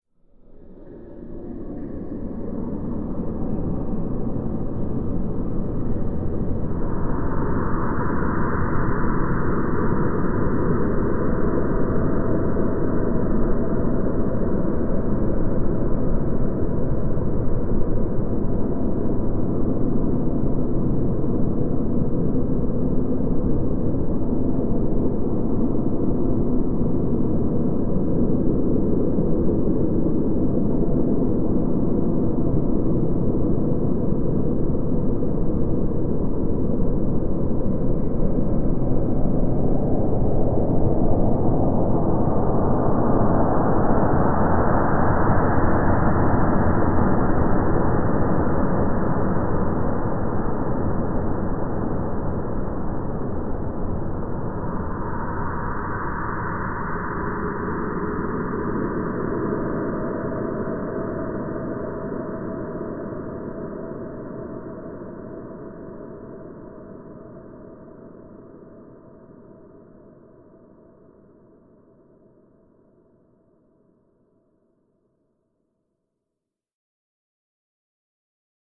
Huge Airy Cave Ambience Airy Cave, Huge